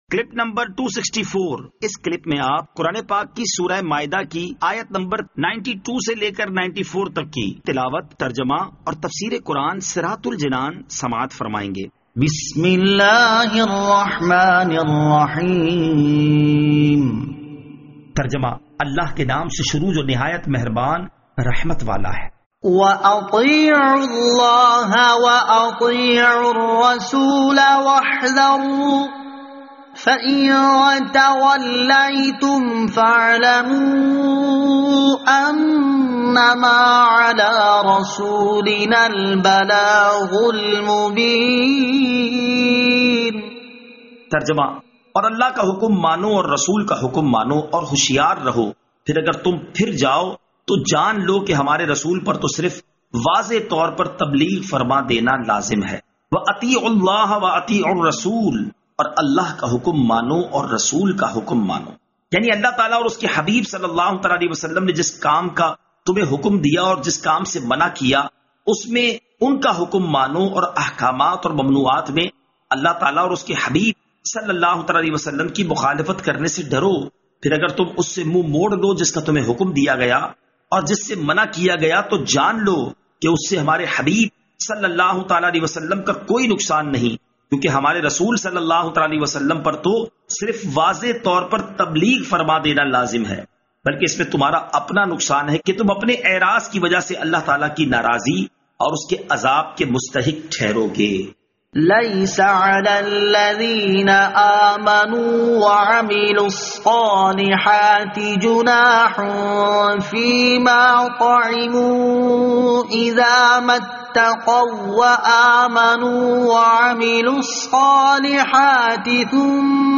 Surah Al-Maidah Ayat 92 To 94 Tilawat , Tarjama , Tafseer